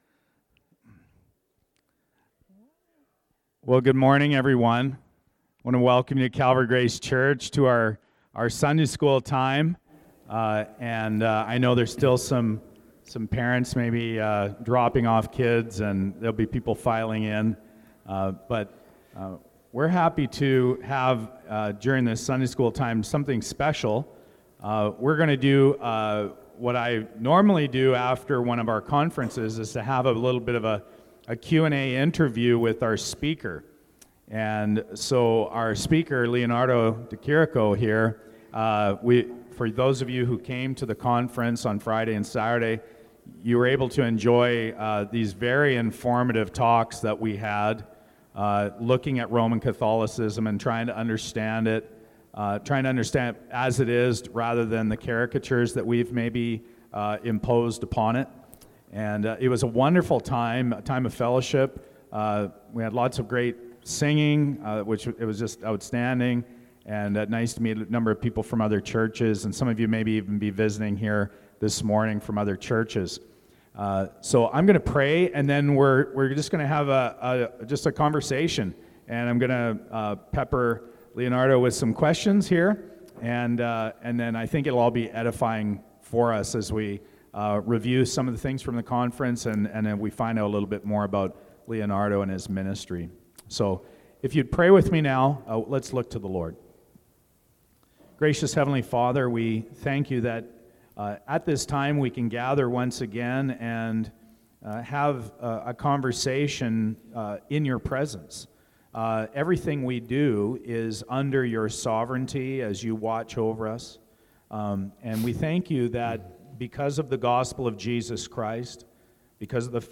Conference Sunday Q&A. Yes and Yes.
Audio recordings of teaching from Calvary Grace Church of Calgary.